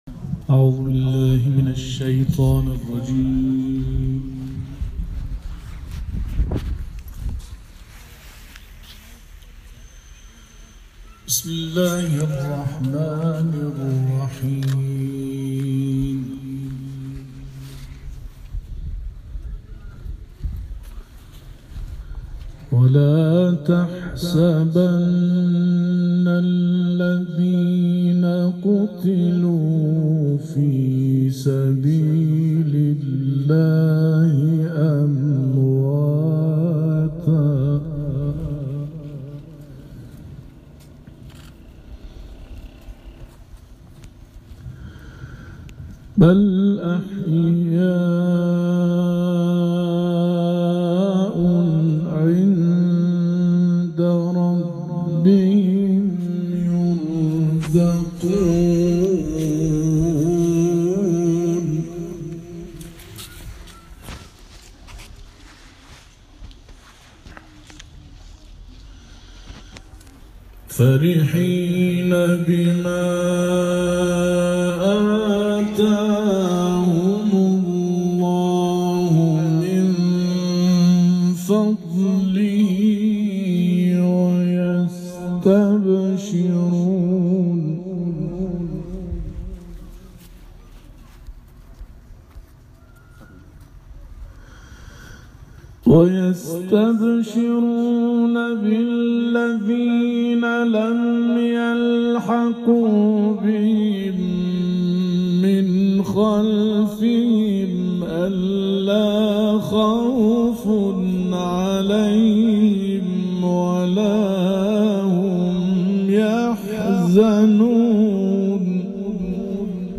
قاریان این کاروان در محافل قرآنی گلزار شهداء و مسجد جامع قلعه گنج، آیاتی از کلام الله مجید را قرائت کردند.